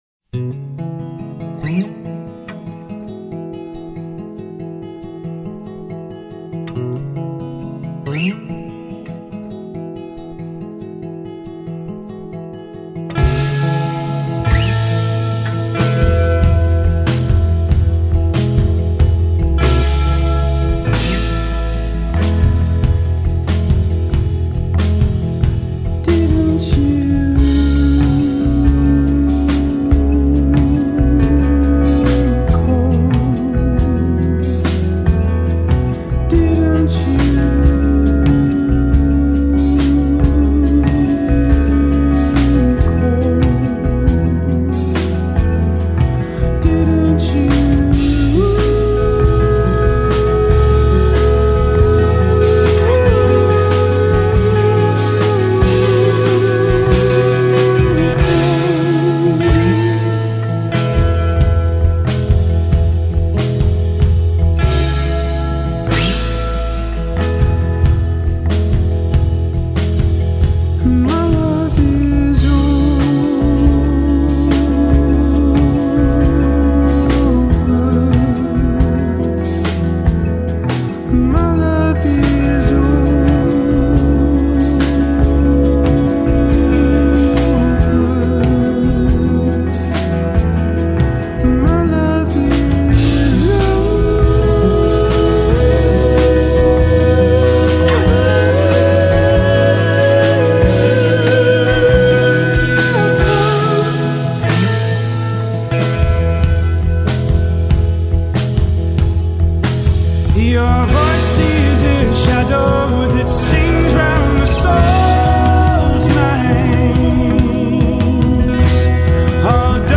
alternative-folk
haunting falsetto